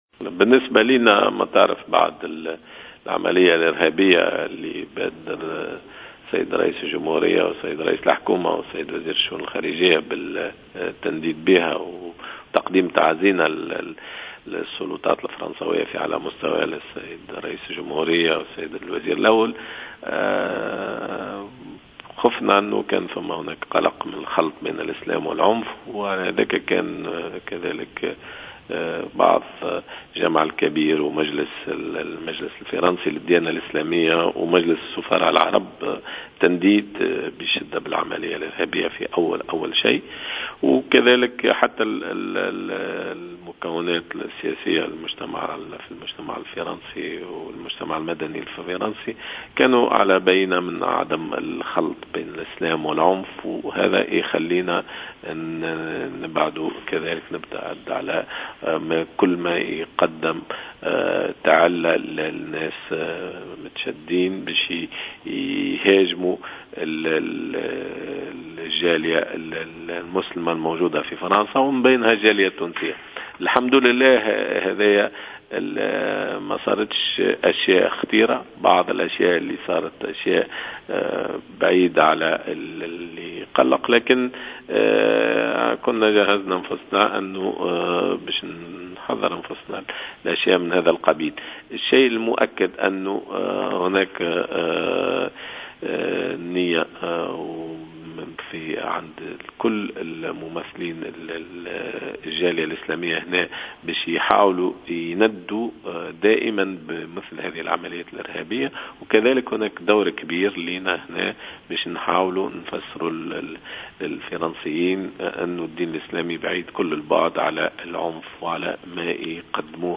أكد السفير التونسي لدى فرنسا، محمد علي الشيحي في تصريح للجوهرة أف أم اليوم...